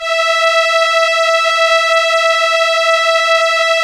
Index of /90_sSampleCDs/Keyboards of The 60's and 70's - CD1/STR_Elka Strings/STR_Elka Violins